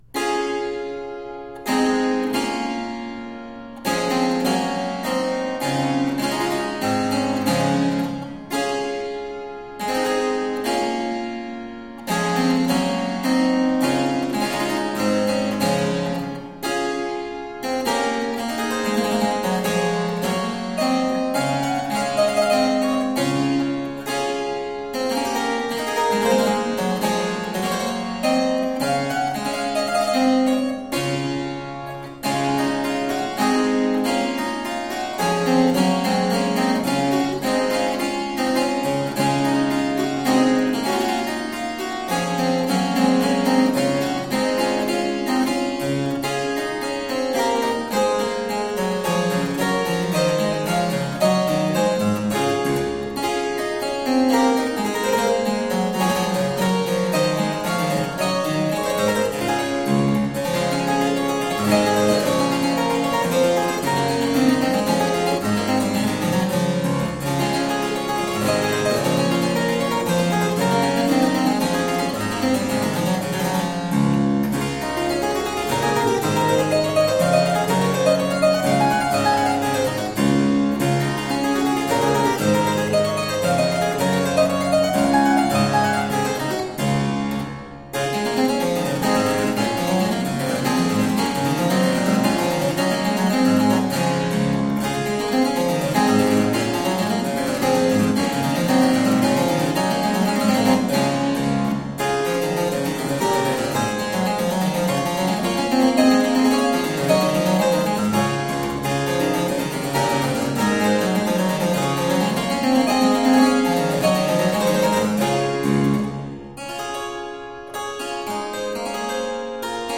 Harpsichord and fortepiano classics.